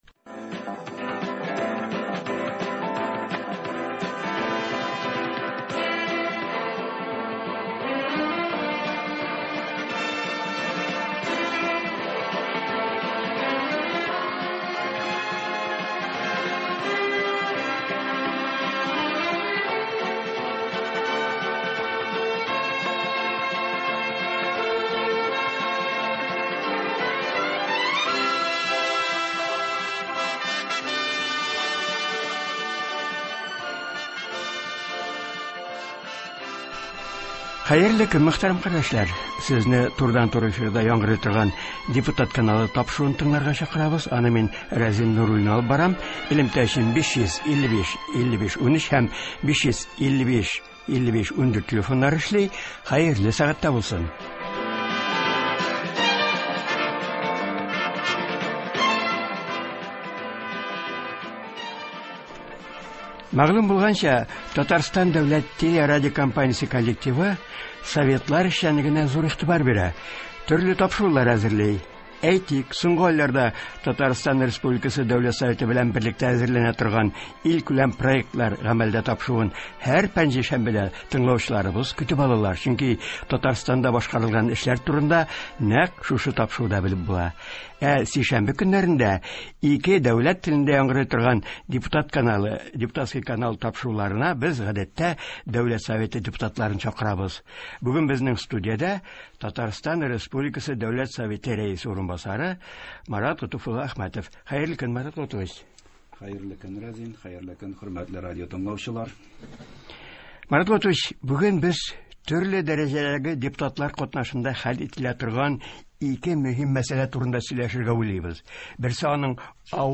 Бүген турыдан-туры эфирда “Депутат каналы” тапшыруында Татарстан Республикасы Дәүләт Советы Рәисе урынбасары Марат Әхмәтов булды. Әңгәмәдә төрле дәрәҗәдәге депутатлар катнашында хәл ителә торган ике мөһим мәсьәлә турында сүз барды. Берсе аның авыл җирлекләрендә башланып китеп, хәзер шәһәр халкы да күтәреп алган, үзара салым мәсьәләсе.